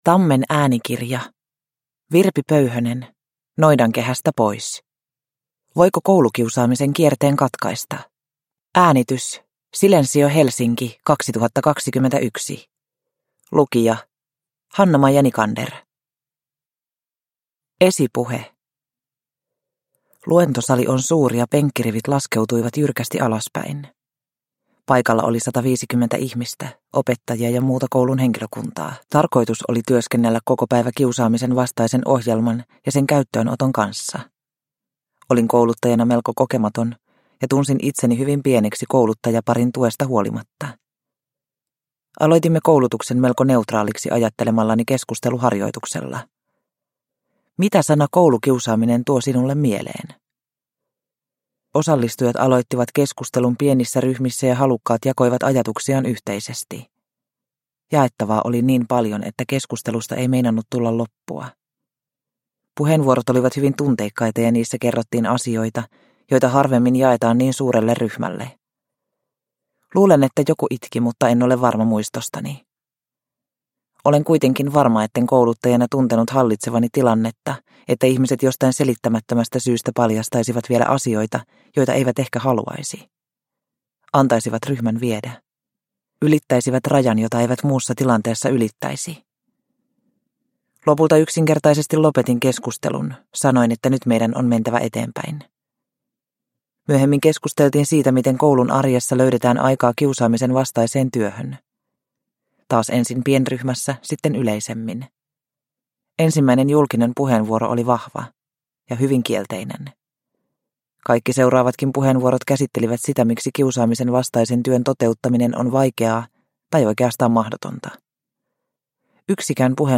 Noidankehästä pois – Ljudbok – Laddas ner